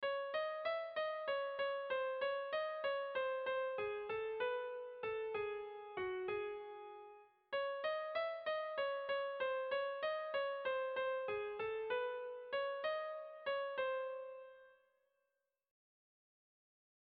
Tragikoa
A1A2